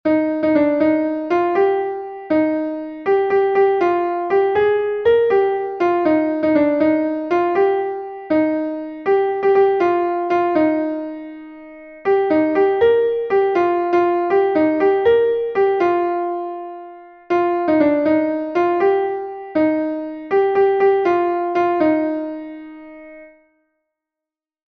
Ar Chouanted is a Bale from Brittany